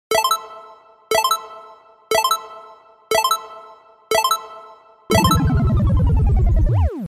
5 Second Timer sound effects free download